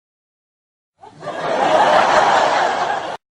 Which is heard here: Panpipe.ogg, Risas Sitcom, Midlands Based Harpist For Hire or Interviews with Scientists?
Risas Sitcom